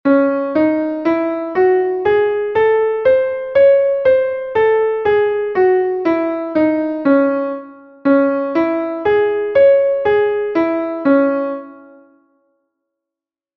Entoación a capella
Escala e arpexio:
escala_arpegio_do_num__m.mp3